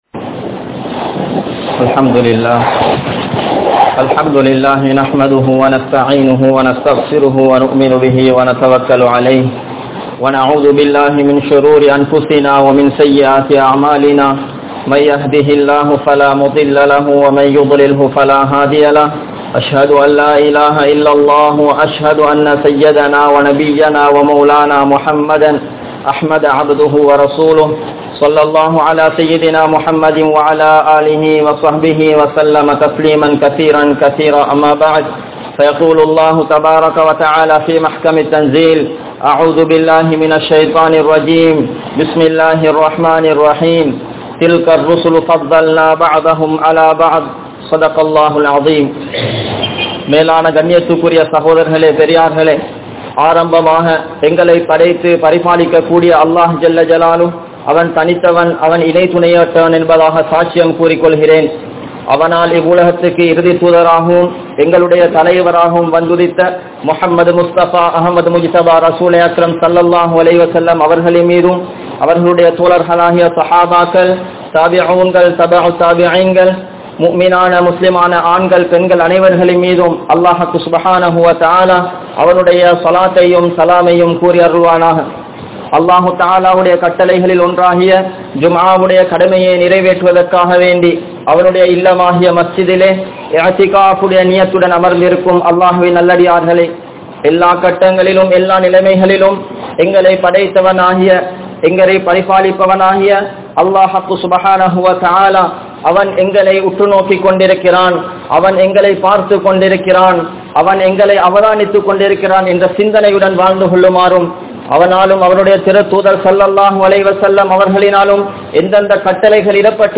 Nabi Eesa(Alai)Avarhalin Mun Maathirihal (நபி ஈஸா(அலை) அவர்களின் முன்மாதிரிகள்) | Audio Bayans | All Ceylon Muslim Youth Community | Addalaichenai
Masjidhul Hakam Jumua Masjidh